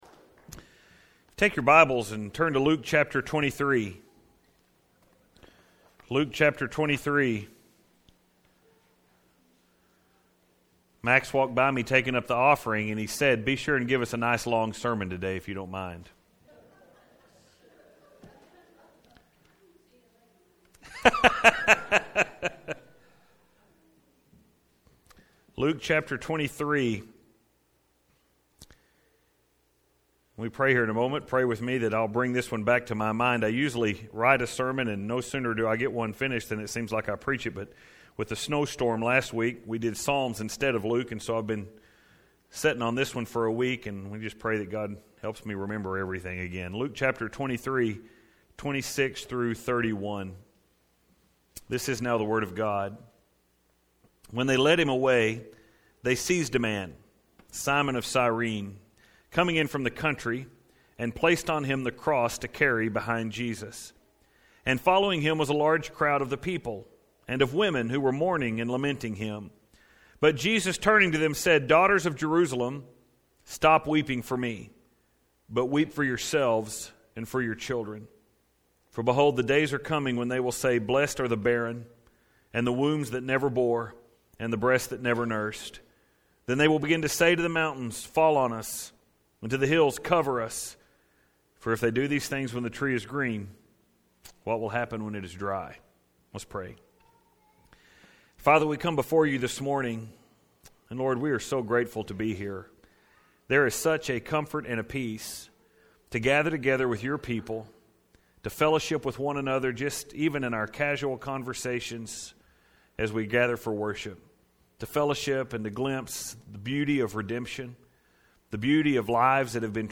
The Last Sermon (Luke 23:26-31)